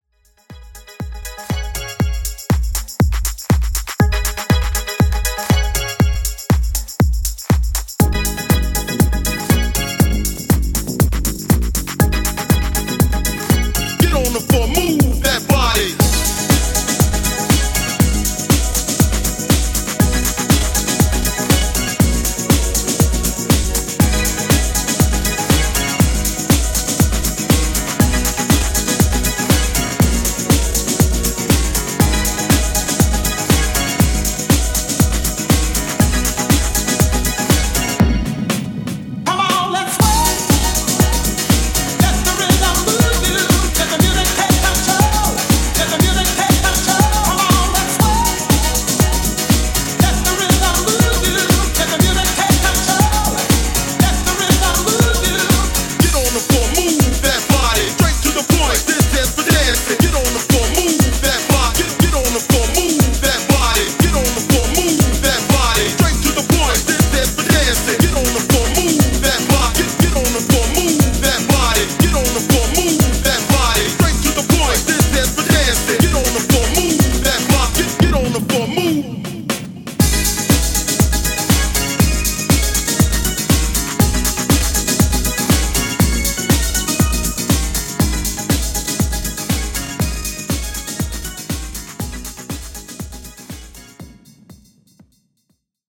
Classic Hip-House)Date Added